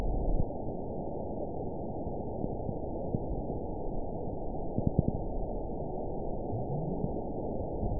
event 921705 date 12/16/24 time 23:34:24 GMT (4 months, 3 weeks ago) score 9.08 location TSS-AB04 detected by nrw target species NRW annotations +NRW Spectrogram: Frequency (kHz) vs. Time (s) audio not available .wav